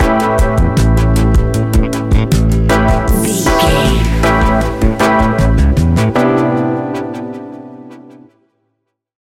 Ionian/Major
A♭
laid back
Lounge
sparse
new age
chilled electronica
ambient
atmospheric
morphing